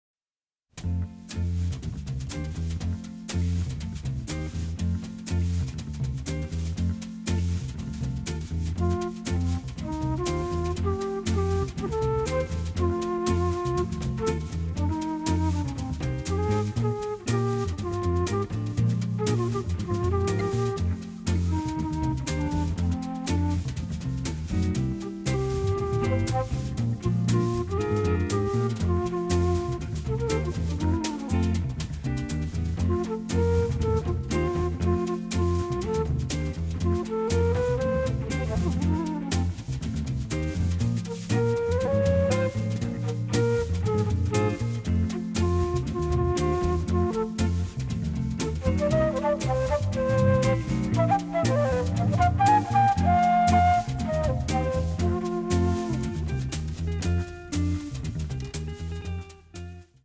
shake #2